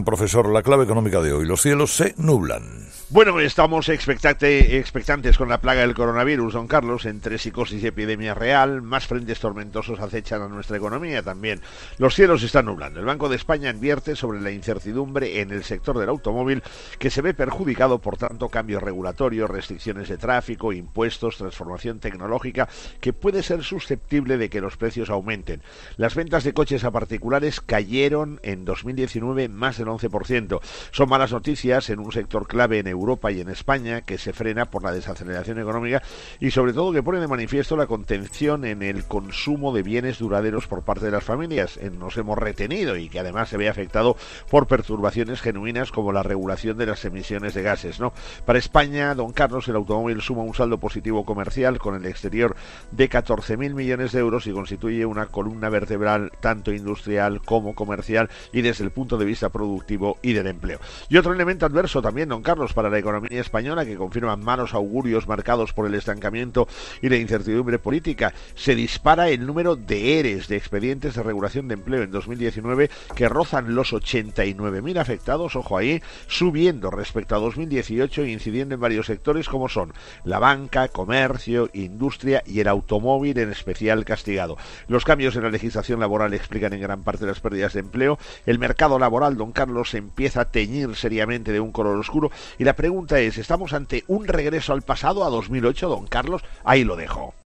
El resumen económico del día con el profesor Gay de Liébana y su particular 'mirada económica' en 'Herrera en COPE'.